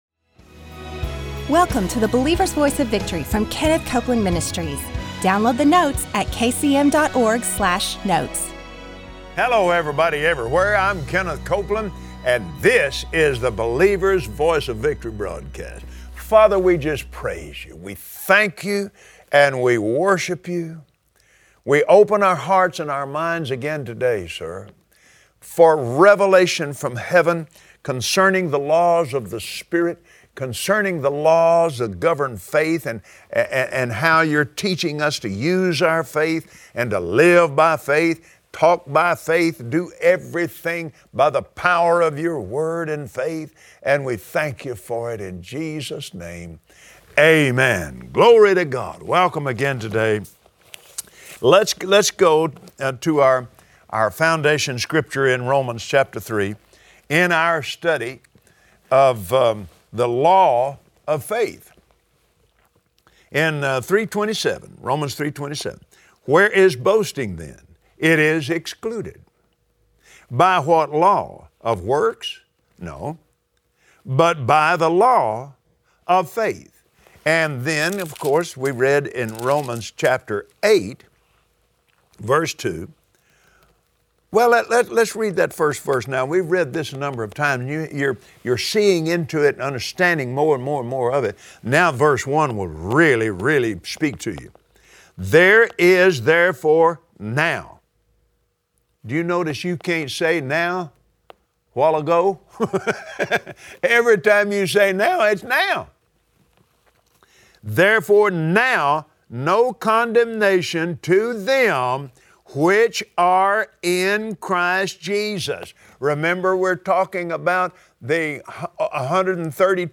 These and other questions are answered today, on the Believer’s Voice of Victory, as Kenneth Copeland continues his study from scripture on the subject of faith.